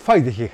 津軽方言：古代の発音
しかし、津軽方言では、ハ行から始まる言葉は、p- とは h- の中間音である f-　として残っていることが確認できます！